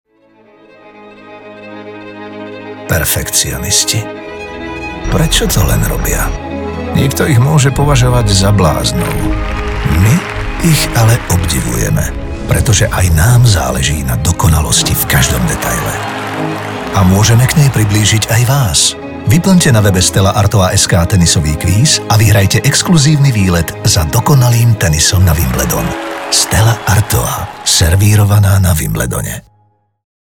Native speaker Male 20-30 lat
Experienced voice artist whose native language is Czech.
Spot reklamowy